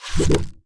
Trigger Appear Sound Effect
trigger-appear.mp3